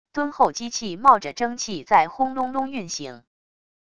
敦厚机器冒着蒸汽在轰隆隆运行wav音频